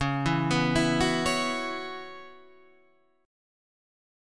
Dbm11 chord